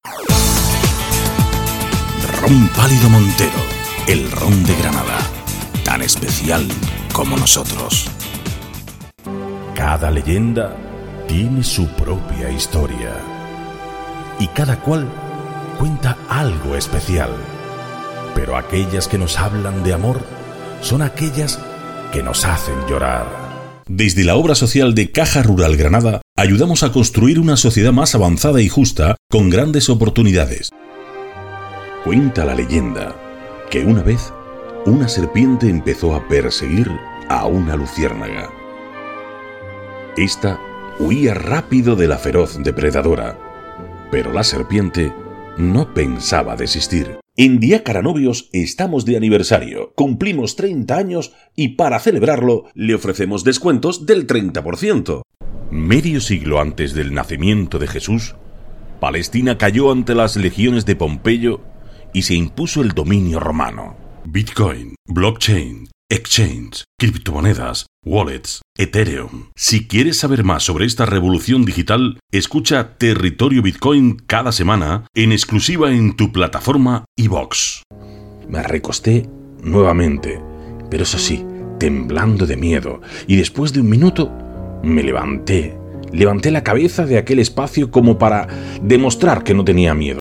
Micrófono/ Senheisher MD-421 II Mesa: Behringer Xenyx X1622USB Ordenador: Pentium 7. Tarjeta Sound Blaster
spanisch
Sprechprobe: Sonstiges (Muttersprache):